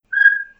bip_02.wav